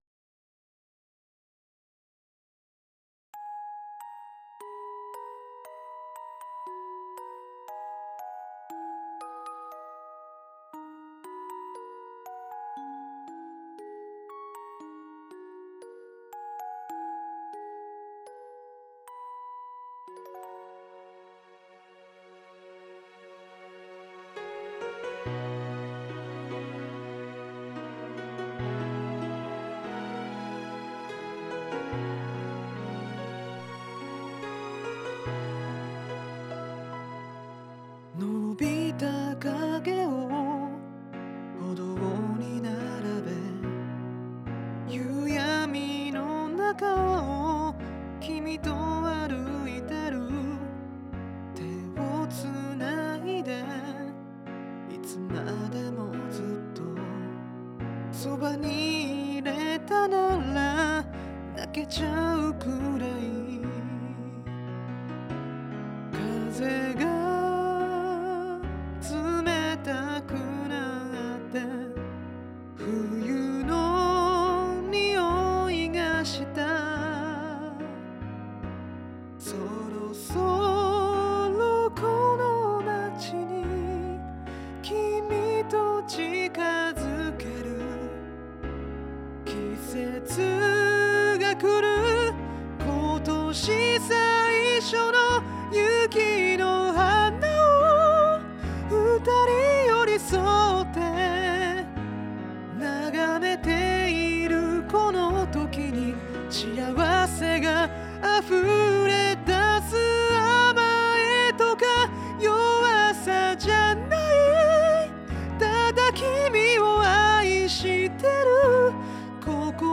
講師の歌唱音源付き記事
音量注意！
※カラオケ音源はこちらからお借りしました。